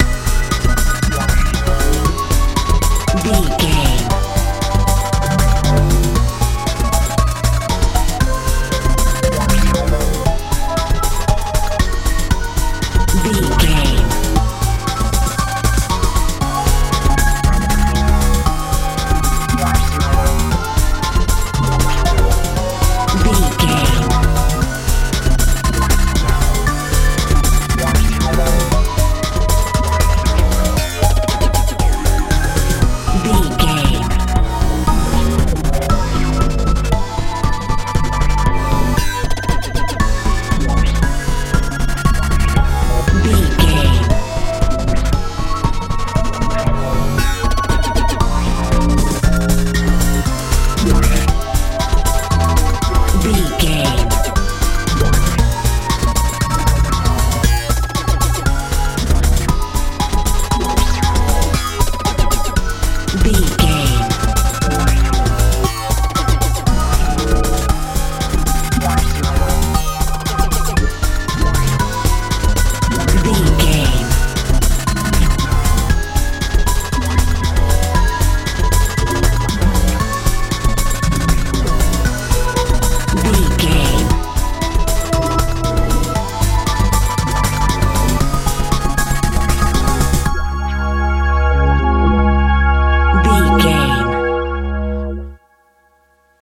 modern pop feel
Ionian/Major
futuristic
synthesiser
bass guitar
drums
strange
industrial